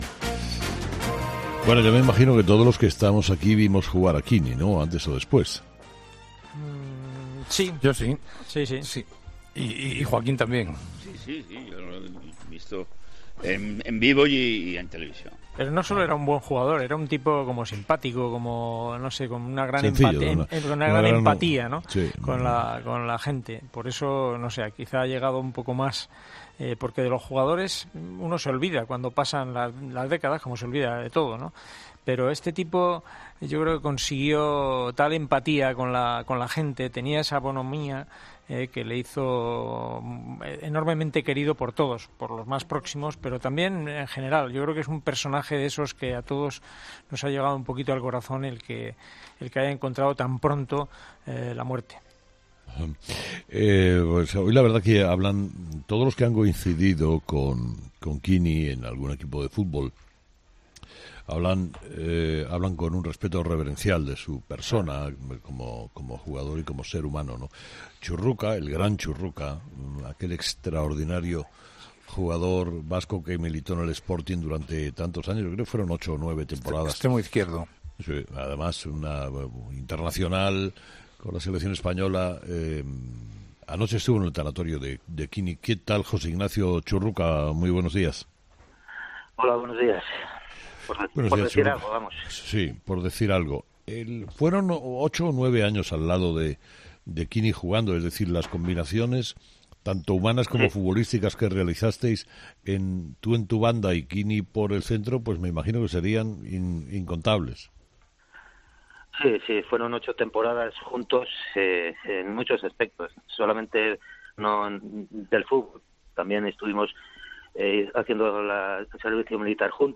En una entrevista en 'Herrera en COPE', Churruca ha recordado que el exfutbolista del Sporting tenía la portería "en su mente continuamente".